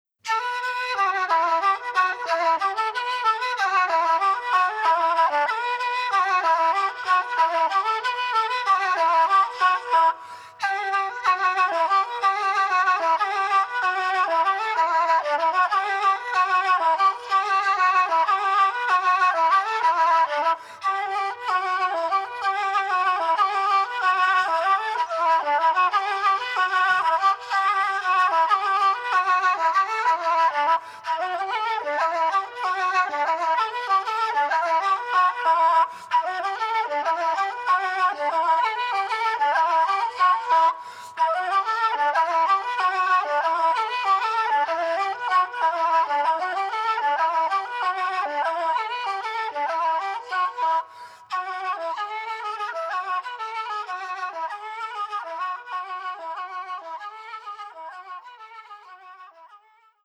traditional Bulgarian kaval music